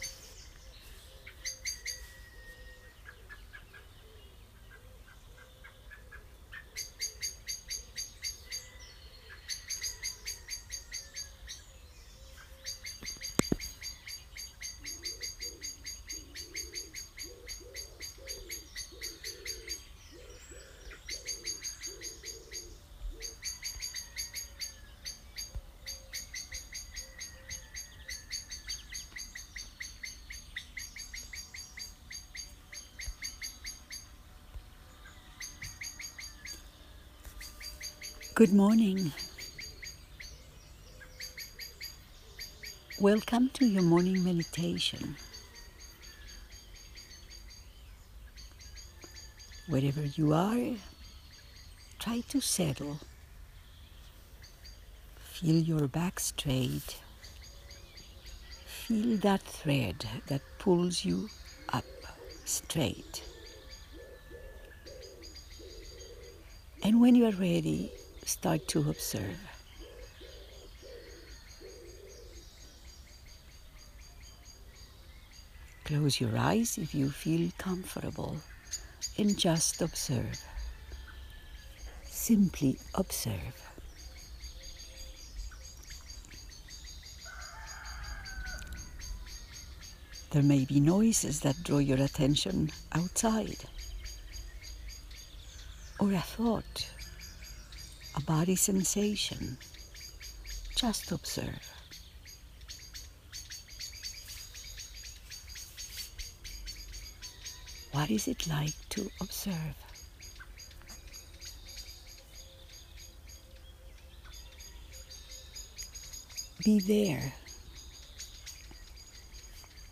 morning-meditation-en.m4a